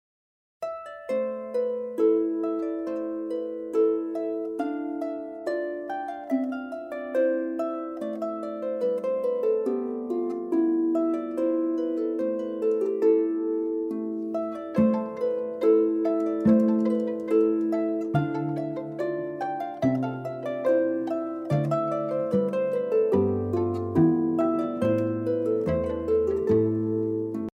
Music of innocence and enchantment.